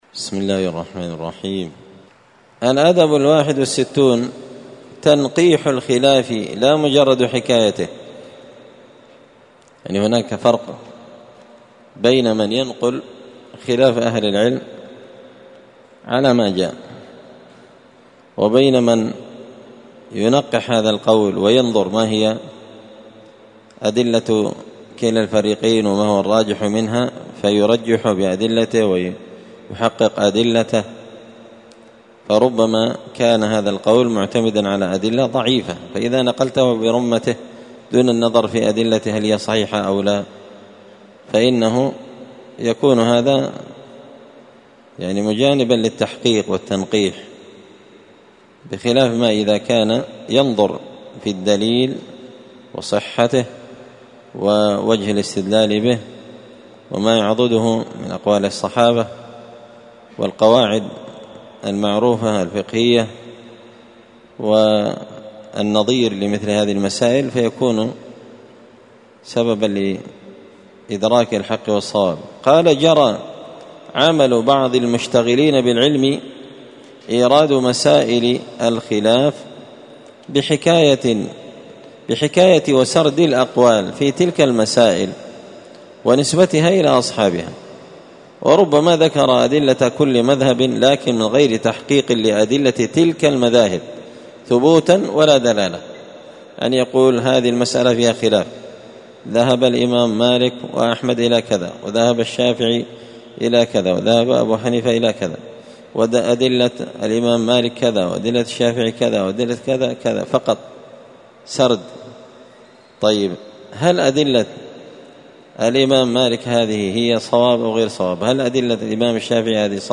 الأثنين 15 ذو الحجة 1444 هــــ | الدروس، النبذ في آداب طالب العلم، دروس الآداب | شارك بتعليقك | 10 المشاهدات
مسجد الفرقان قشن_المهرة_اليمن